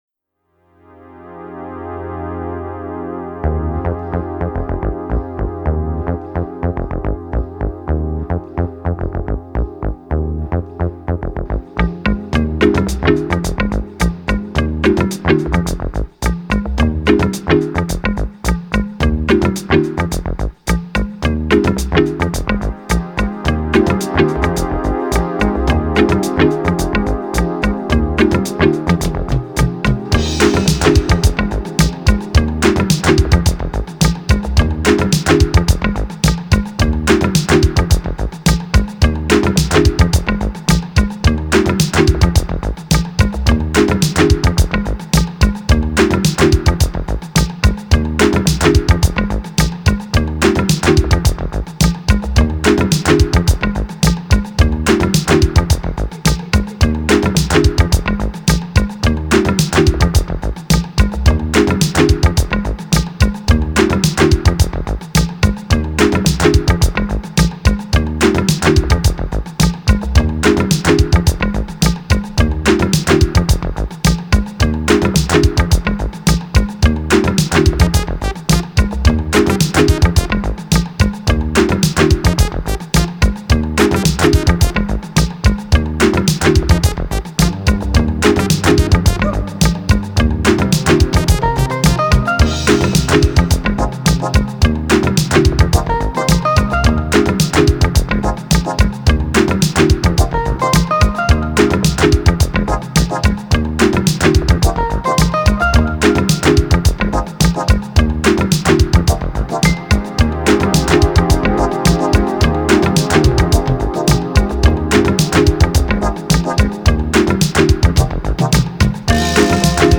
موسیقی فانک سینث-فانک